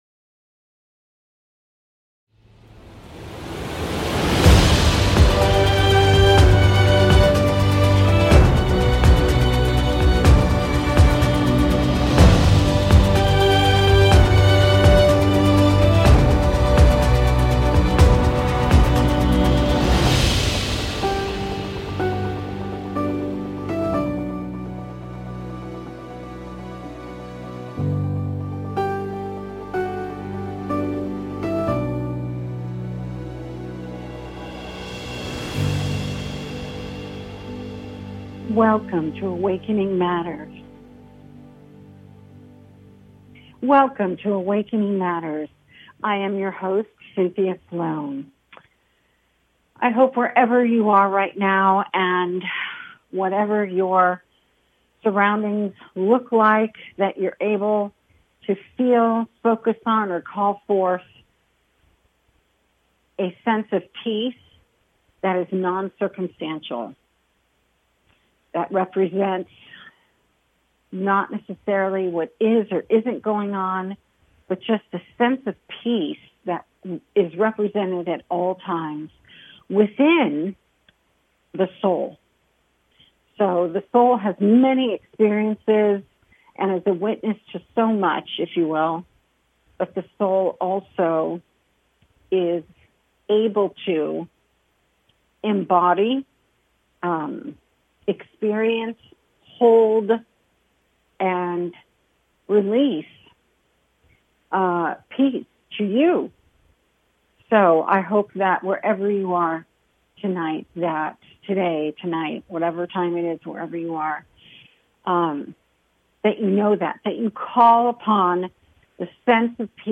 Talk Show Episode, Audio Podcast, Awakening Matters and Near Death Experiences on , show guests , about Near Death Experiences, categorized as Health & Lifestyle,Alternative Health,Energy Healing,Philosophy,Psychology,Self Help,Spiritual,Medium & Channeling,Psychic & Intuitive
A spiritual dialogue that invites divine wisdom, joy and laughter.